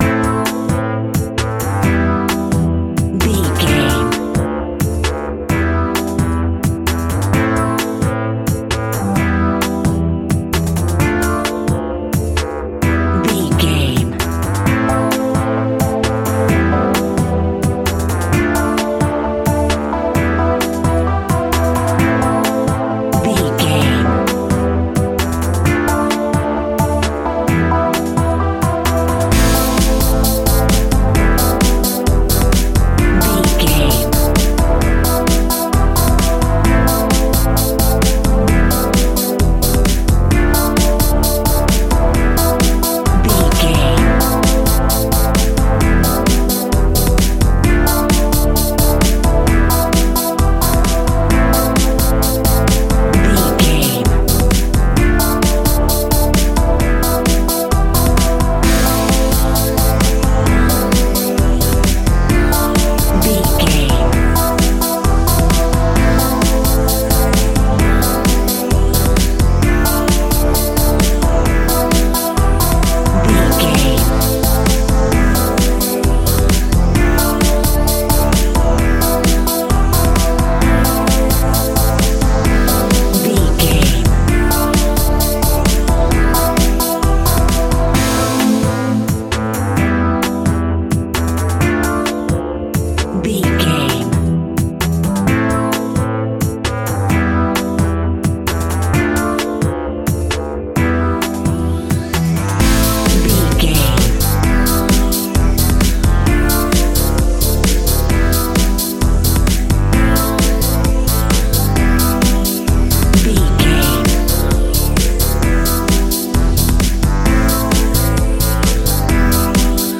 Grooving Electric Funky House.
Aeolian/Minor
funky
uplifting
energetic
synthesiser
drum machine
funky house
upbeat
funky guitar
synth bass